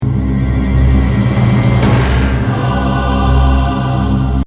描述：一个可怕的声音效果类型的循环，在管弦乐打击后出现了闹鬼的合唱团，会让他们在座位上跳起来。
Tag: 90 bpm Ambient Loops Orchestral Loops 759.67 KB wav Key : Unknown